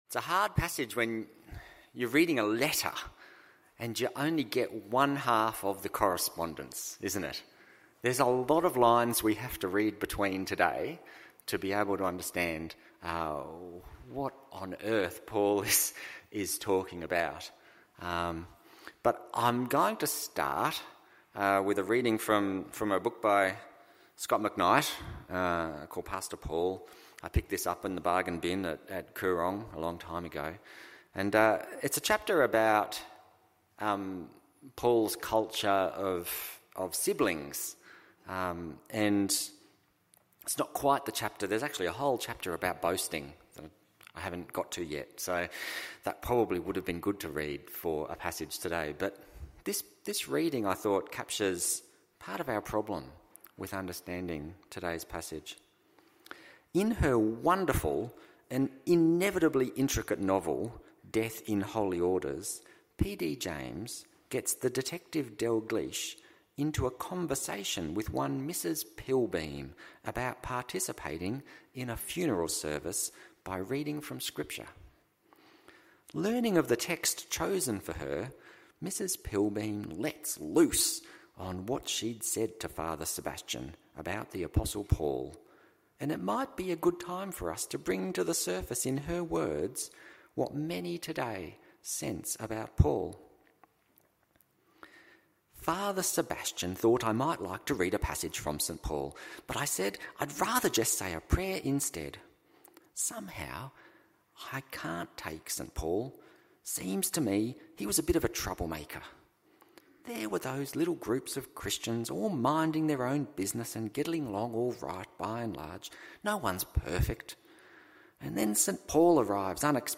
2023 Defending Ministry Preacher
Eternal Glory Passage: 2 Corinthians 10:1-18 Service Type: AM Service « The gospel of giving Why Tolerate My Foolishness?